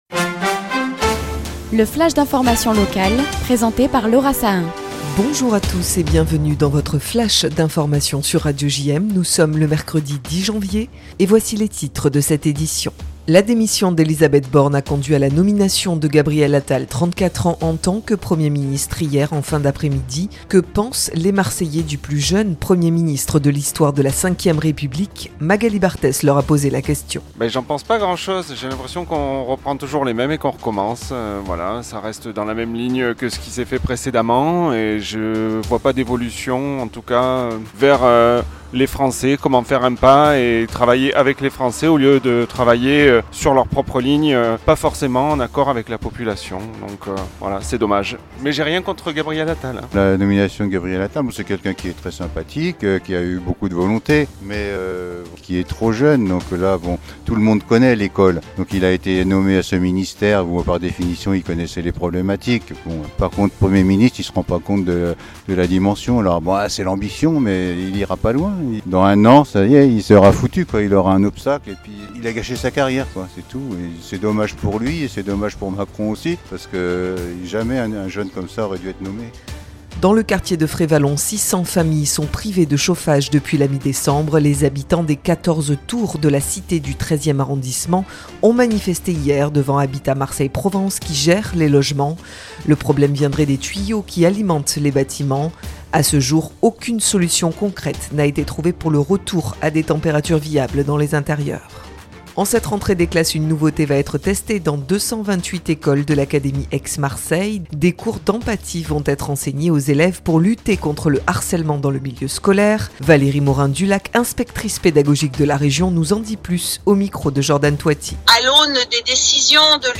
Le flash info